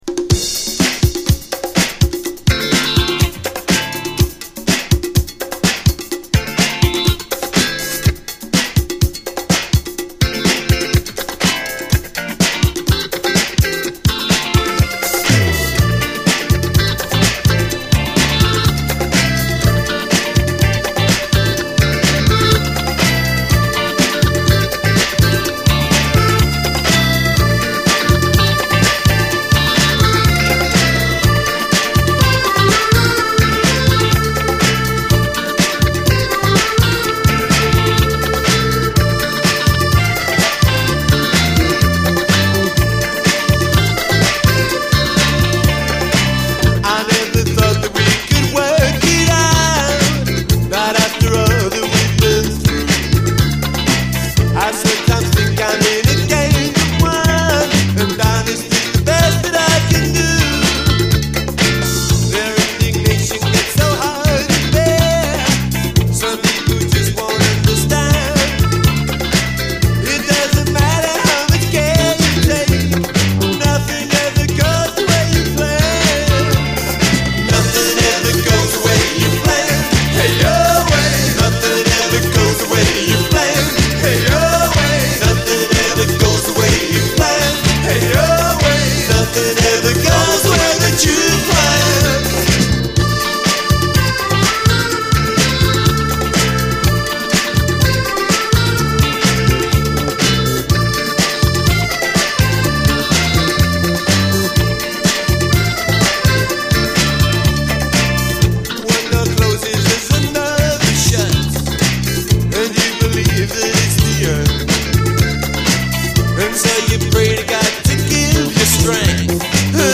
DISCO, 80's～ ROCK, ROCK, FUNK-A-LATINA
トロピカルなディスコ・トラックであるのは勿論、ジプシーっぽい雰囲気もイカしてます！